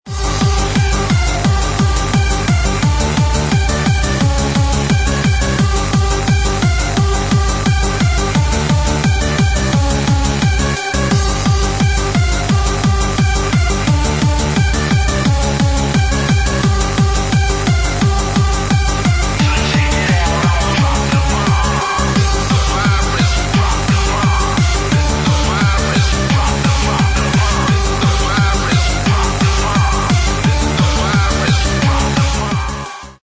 Dance & Trance
Happy Hardcore